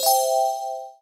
spin.wav